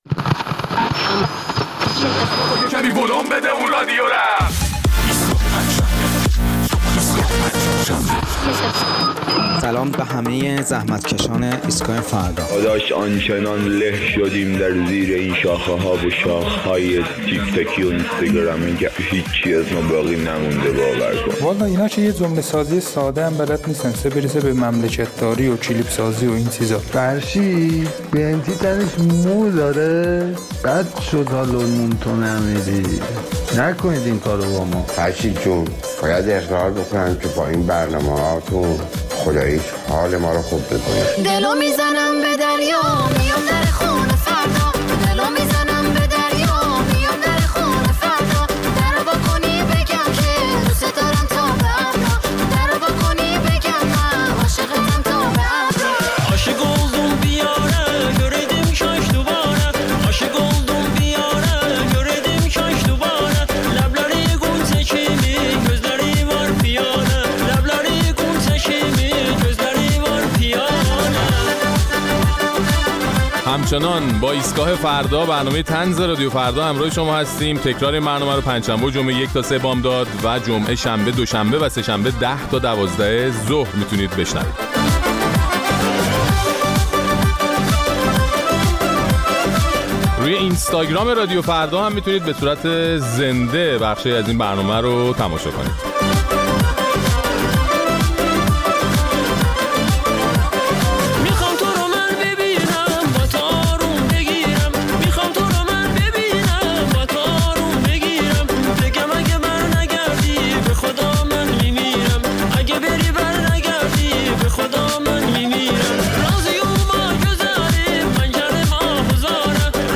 در این برنامه ادامه نظرات شنوندگان ایستگاه فردا را در مورد نتایج تبلیغات گسترده نظام در فضای مجازی و حقیقی برای جا دادن فرهنگ عزاداری‌ در ذهن نسل‌های جدیدتر می‌شنویم.